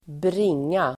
Uttal: [²br'ing:a]